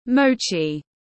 Bánh mô-chi tiếng anh gọi là mochi, phiên âm tiếng anh đọc là /ˈməʊ.tʃi/
Mochi /ˈməʊ.tʃi/